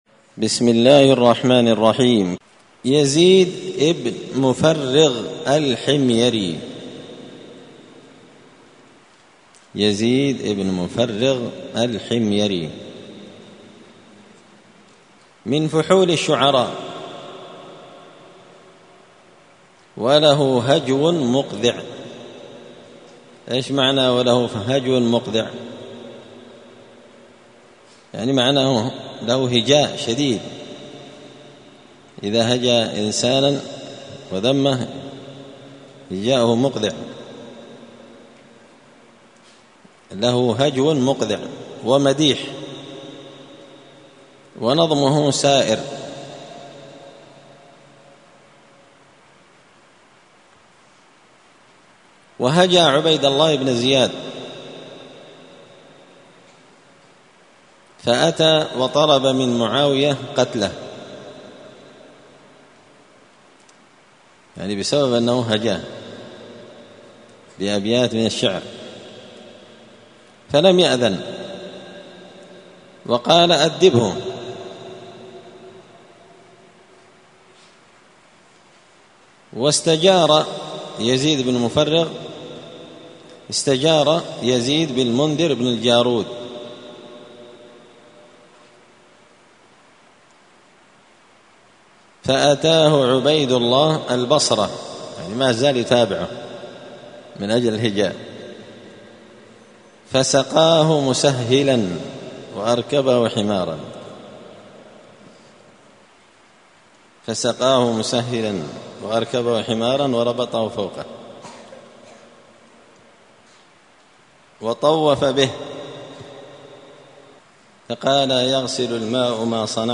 قراءة تراجم من تهذيب سير أعلام النبلاء
دار الحديث السلفية بمسجد الفرقان قشن المهرة اليمن